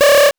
powerup_14.wav